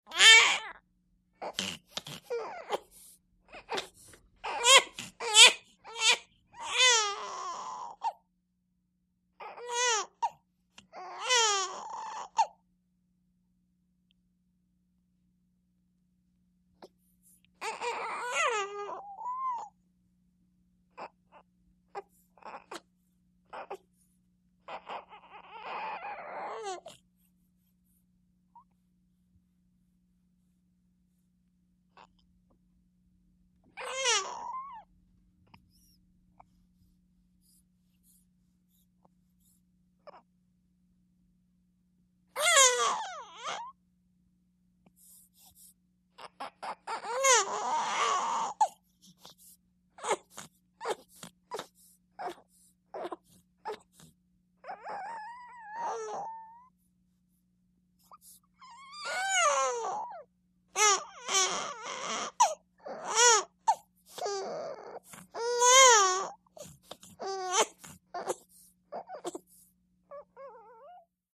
BABY FIVE WEEK OLD: INT: Fretting & crying.